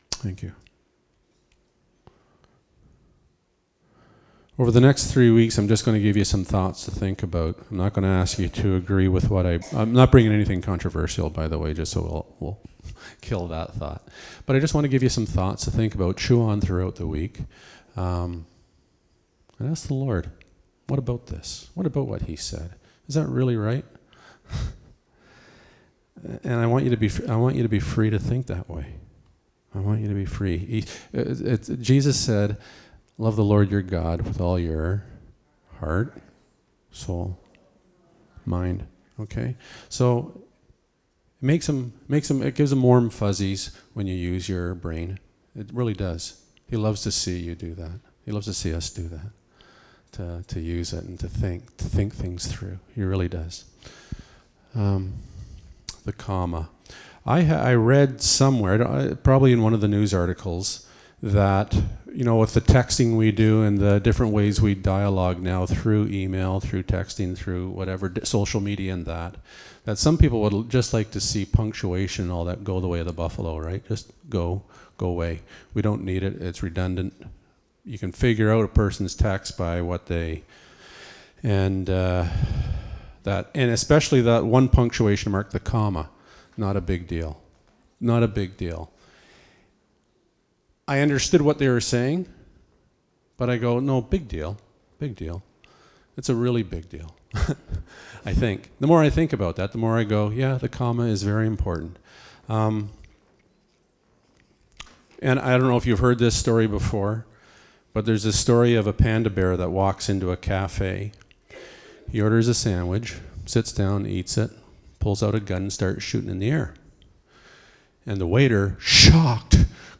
Bible Text: Matthew 20:1-16 | Preacher